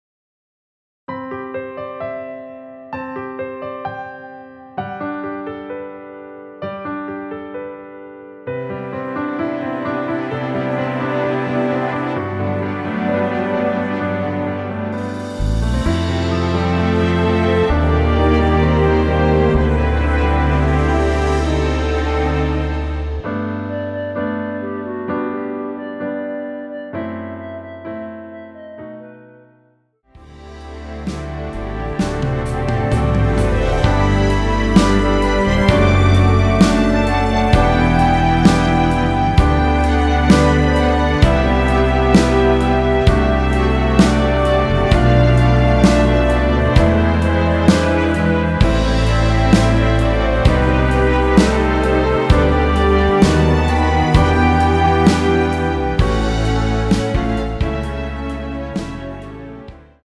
원키에서(-1)내린 멜로디 포함된 MR입니다.
앞부분30초, 뒷부분30초씩 편집해서 올려 드리고 있습니다.
중간에 음이 끈어지고 다시 나오는 이유는